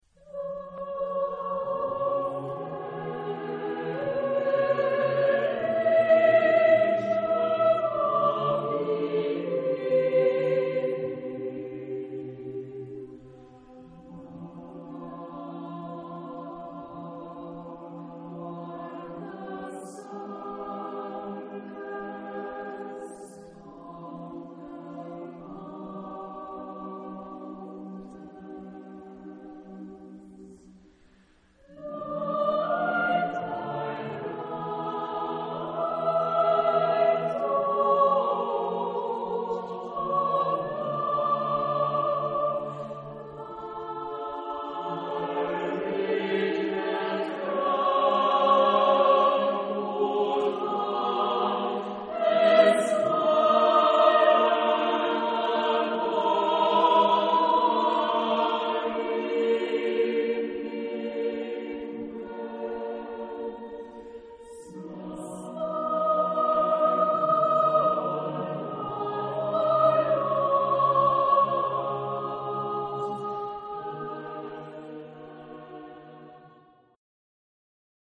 Genre-Style-Forme : contemporain ; Profane
Type de choeur : SSA  (3 voix égales de femmes )
Tonalité : post-tonal